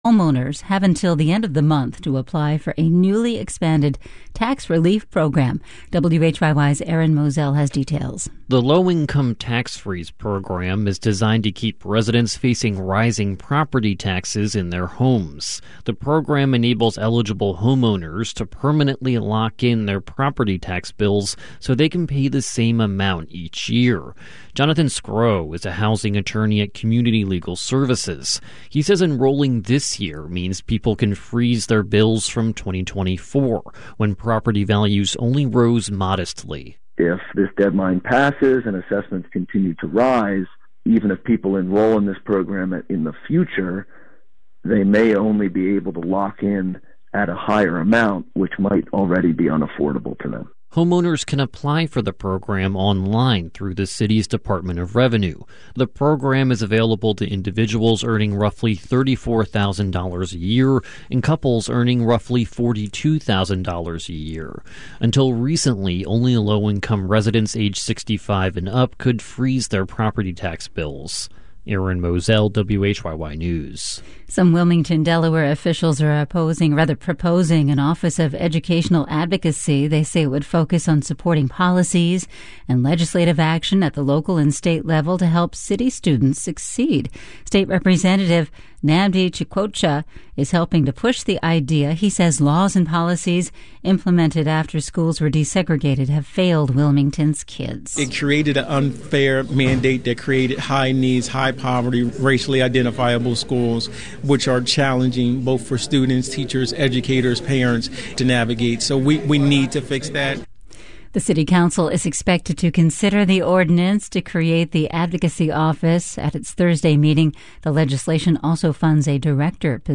WHYY Newscast for Thursday 9:00 a.m.